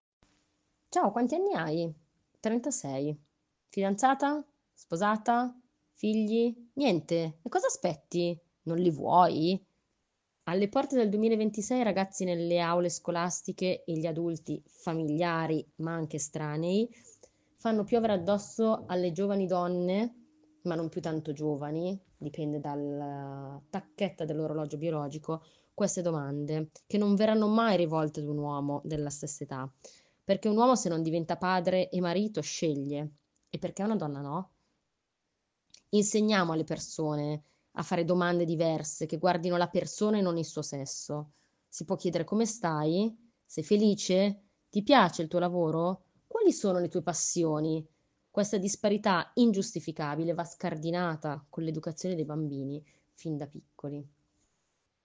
CON UN MESSAGGIO VOCALE